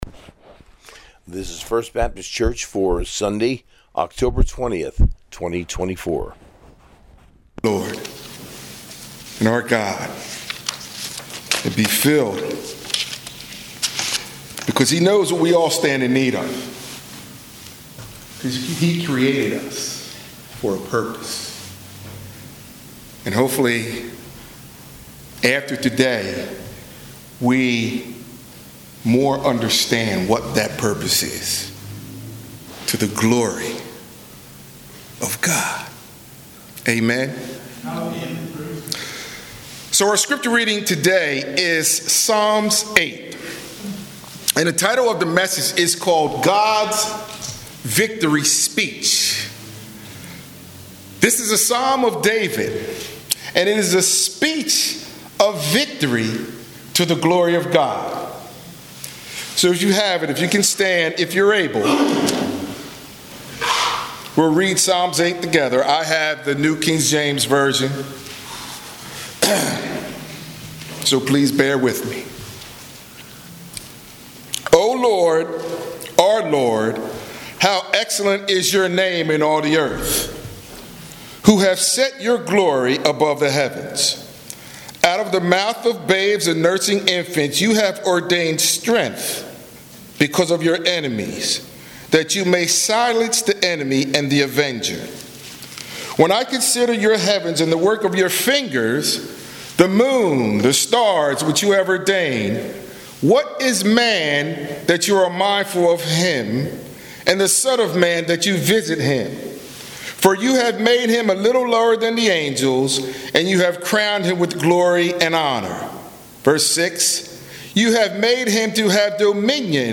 Sunday Sermon,”God’s Victory Speech,” taken from Psalm 8